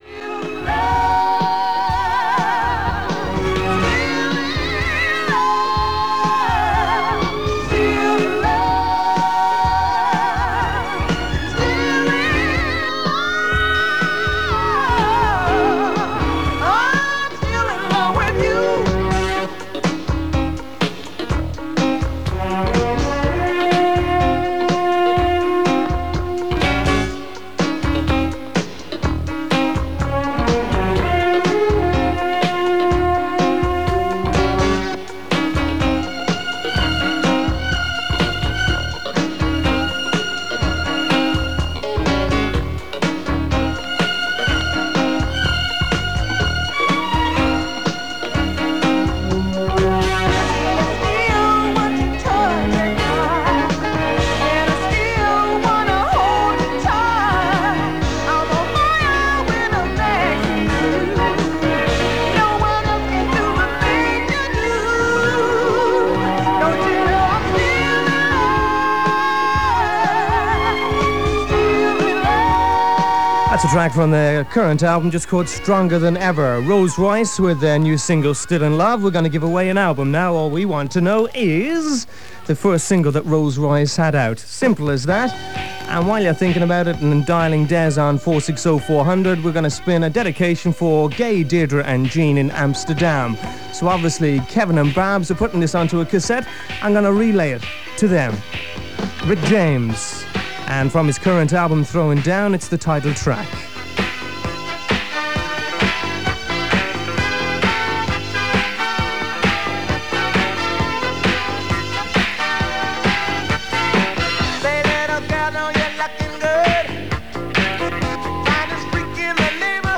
There are dedications from near and far, including from Limerick and even Amsterdam.